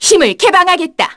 Artemia-Vox_Skill6_kr.wav